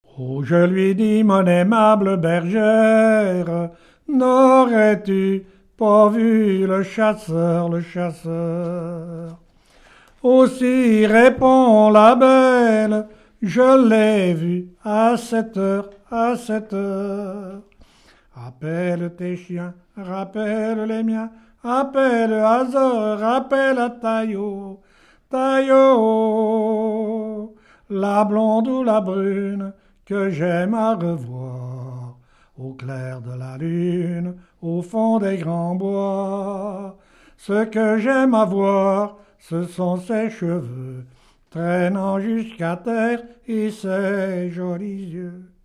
Genre strophique
Pièce musicale inédite